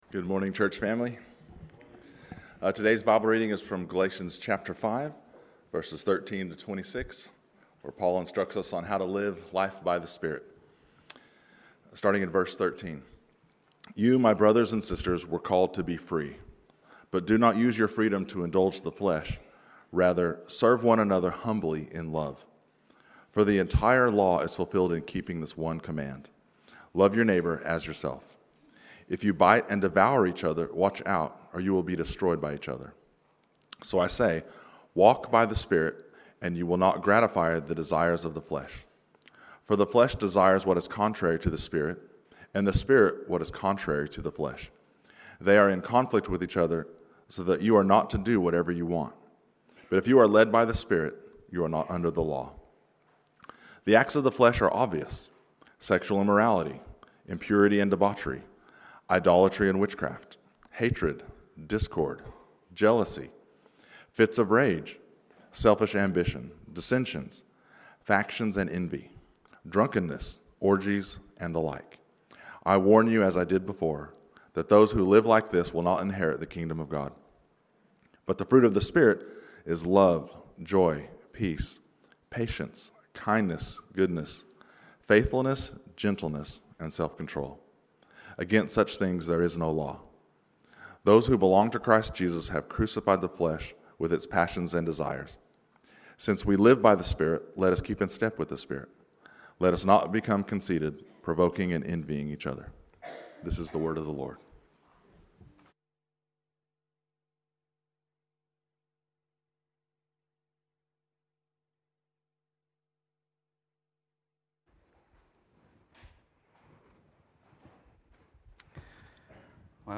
PennoBaps Sermons
Talks from Pennant Hills Baptist